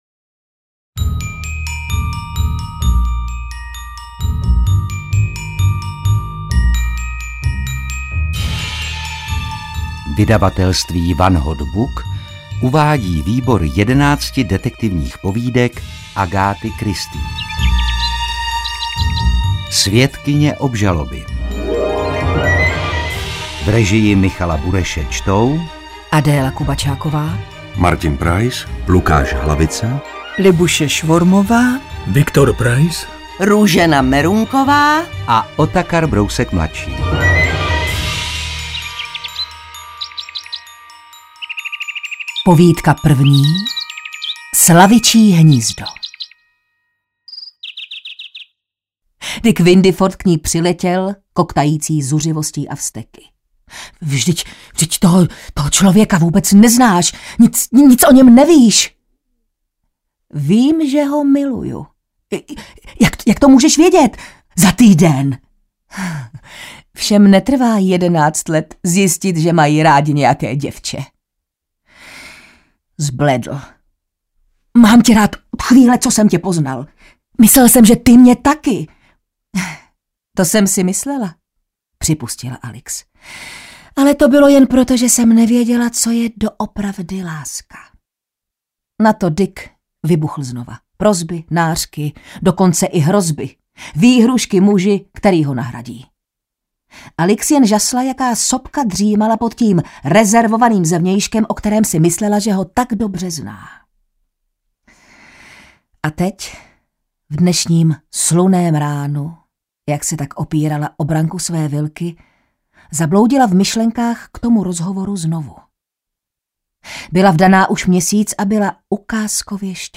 Ukázka z knihy
svedkyne-obzaloby-a-dalsi-povidky-audiokniha